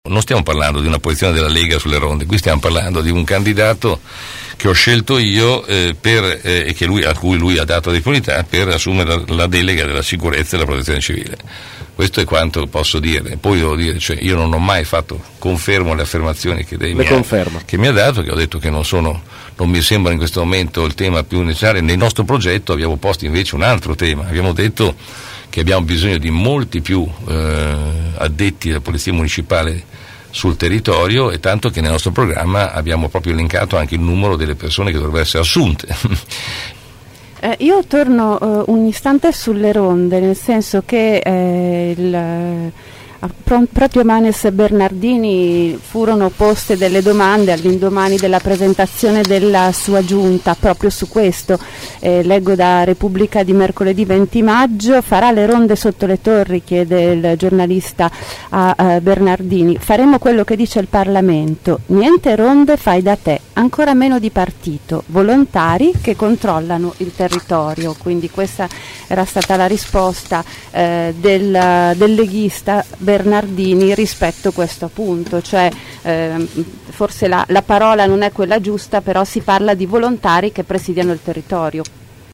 ai nostri microfoni, durante la trasmissione “Angolo B”.